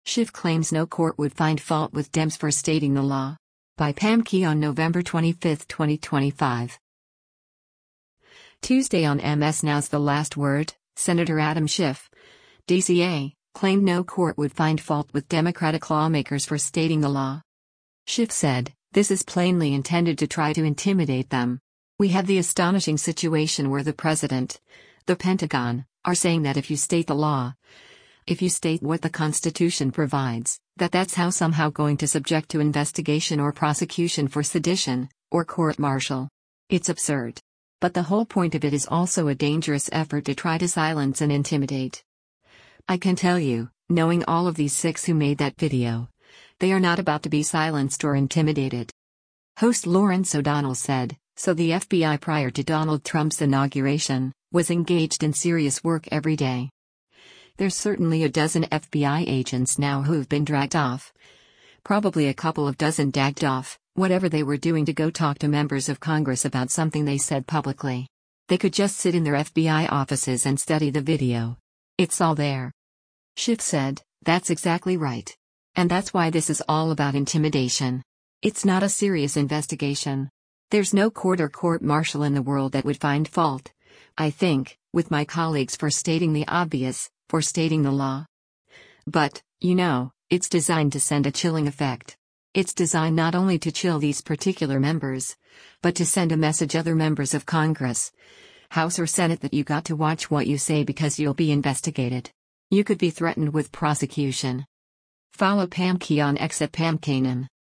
Tuesday on MS NOW’s “The Last Word,” Sen. Adam Schiff (D-CA) claimed no court would find fault with Democratic lawmakers “for stating the law.”